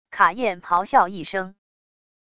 卡宴咆哮一声wav音频